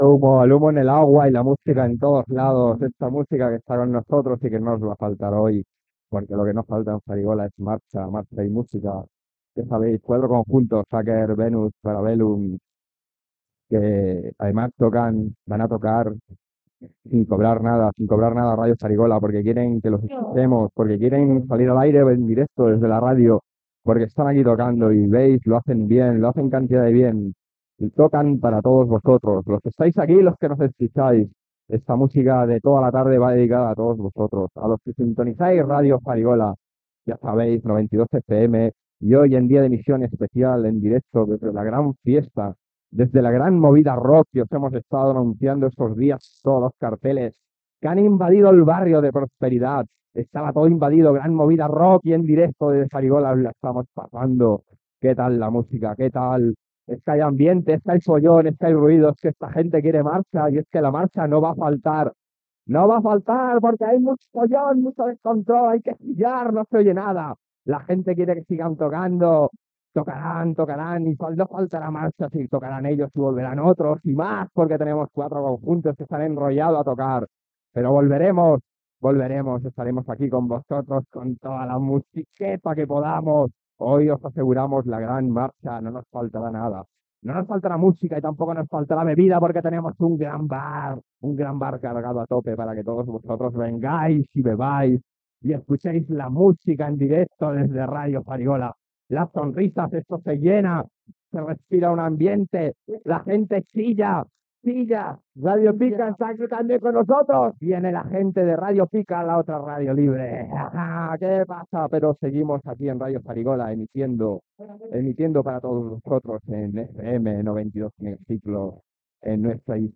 Transmissió de la Festa de Ràdio Farigola a Nou Barris on actúa el grup Venus, amb esment al concert fet a Barcelona per Jethro Tull.